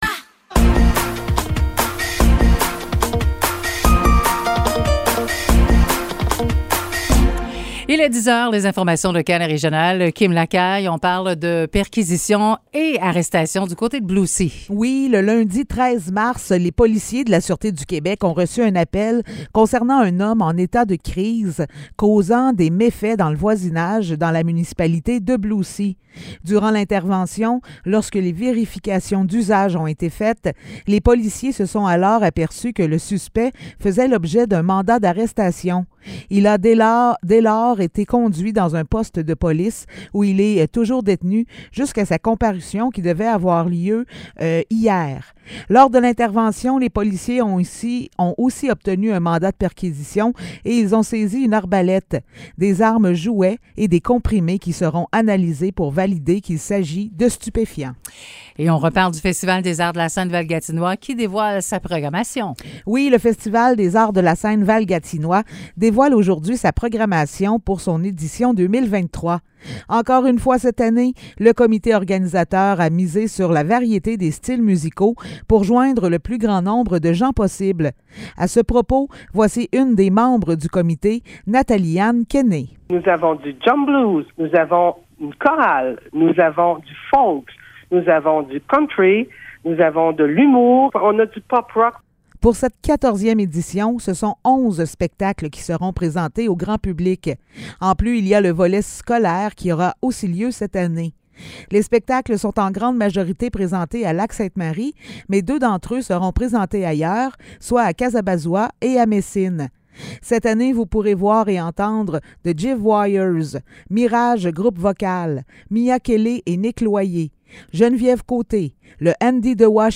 Nouvelles locales - 15 mars 2023 - 10 h